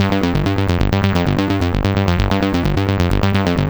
Festa D G 130.wav